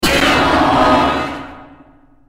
Eyesaur Jumpscare Sound - Botón de Efecto Sonoro